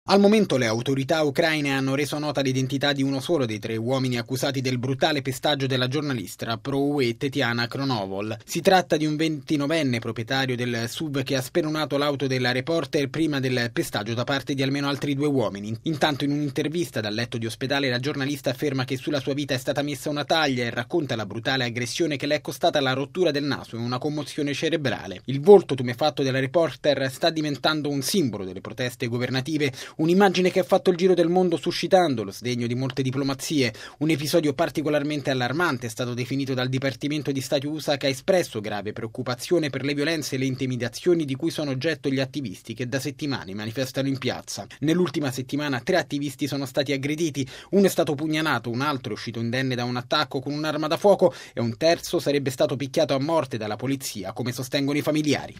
Radiogiornale del 27/12/2013 - Radio Vaticana